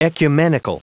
Prononciation du mot ecumenical en anglais (fichier audio)
Prononciation du mot : ecumenical